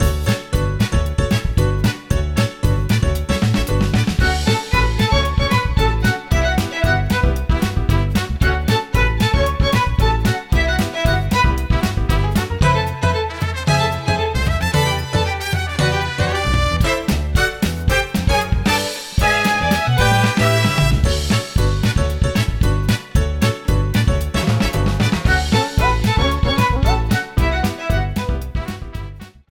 music
Game rip
Cropped, added fade out